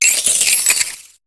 Cri de Trousselin dans Pokémon HOME.